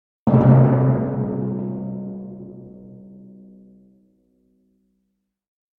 Ниже звуки ударов литавры с разной частотой и силой, которые вы можете послушать онлайн и загрузить на телефон, планшет или компьютер бесплатно.
3. Удар одиночный